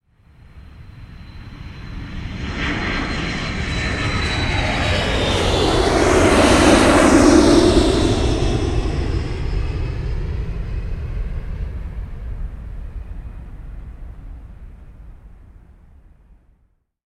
jetapproach.ogg